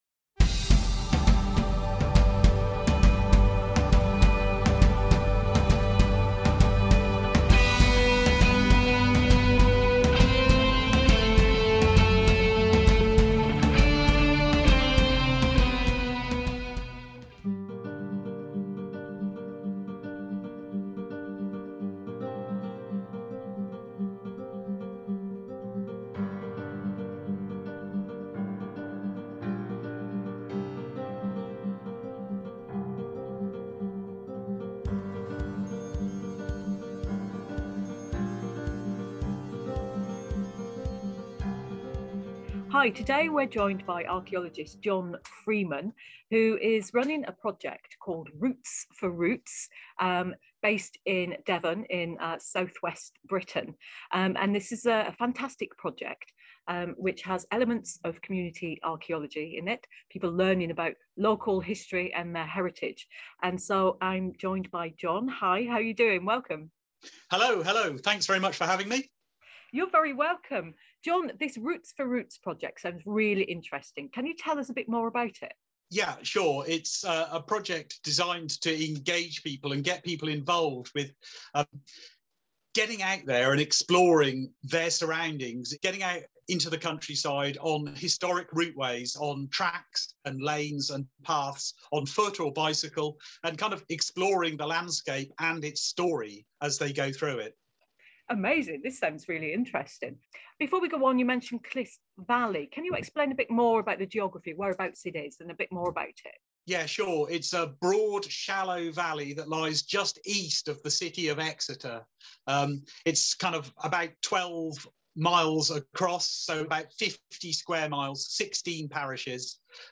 The original video interview is available to listen to here.